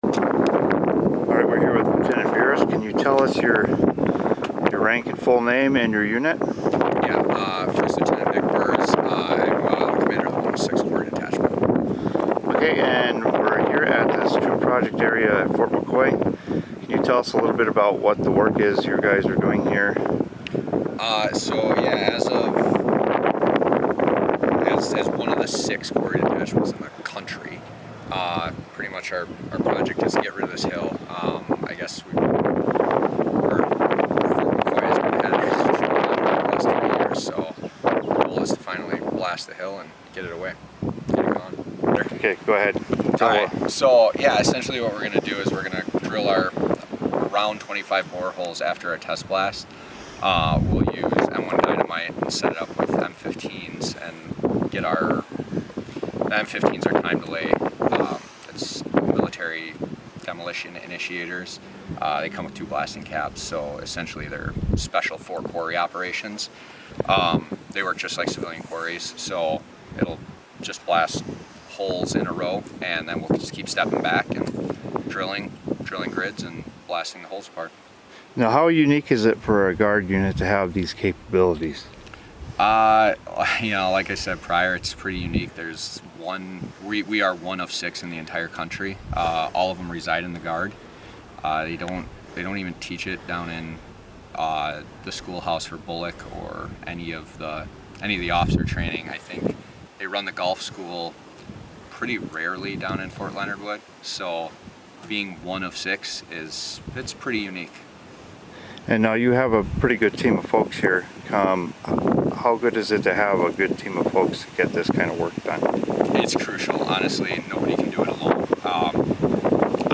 in this interview on June 7